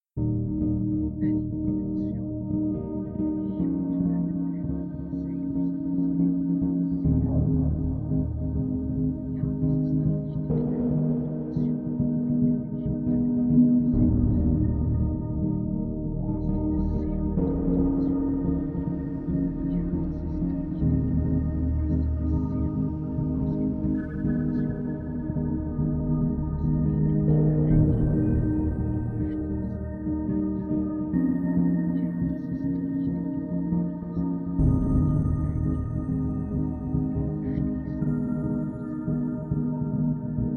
klassisches Subliminal